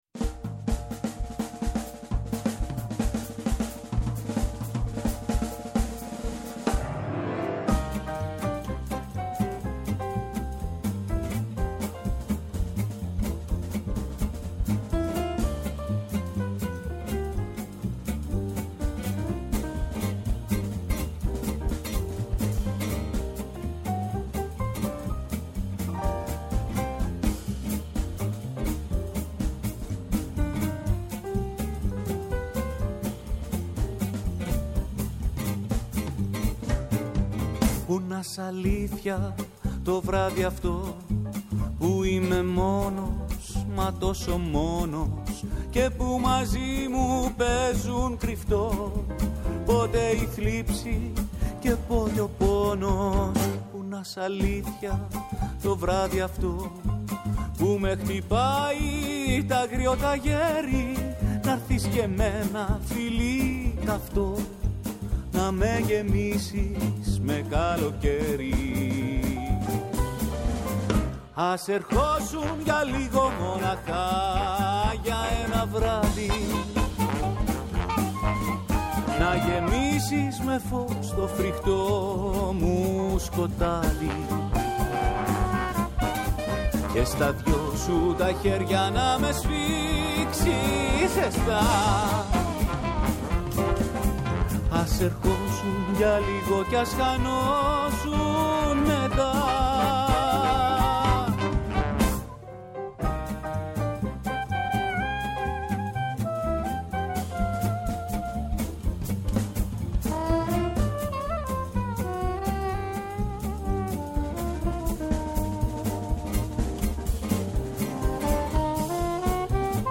Ενστάσεις, αναλύσεις, παρατηρήσεις, αποκαλύψεις, ευχές και κατάρες, τα πάντα γίνονται δεκτά. Όλα όσα έχουμε να σας πούμε στο Πρώτο Πρόγραμμα της Ελληνικής Ραδιοφωνίας, Δευτέρα έως και Πέμπτη, 1 με 2 το μεσημέρι.